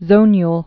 (zōnyl)